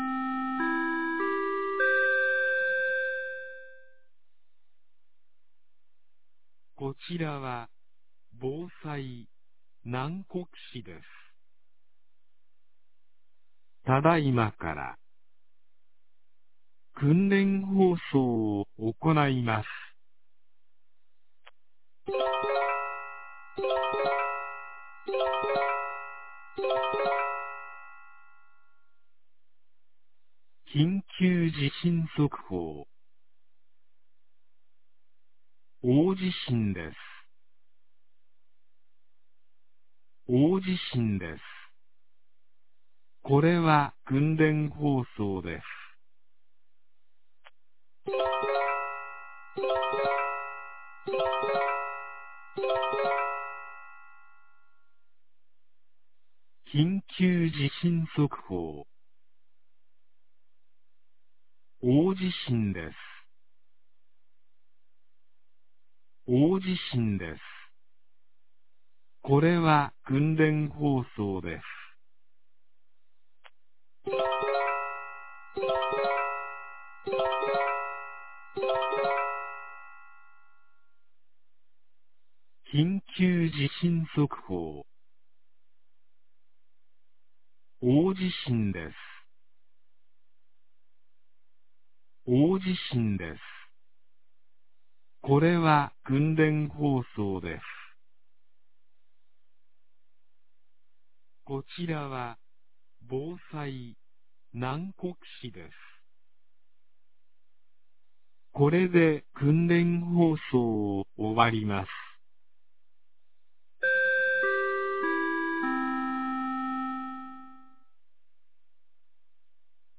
2025年06月18日 10時02分に、南国市より放送がありました。
放送音声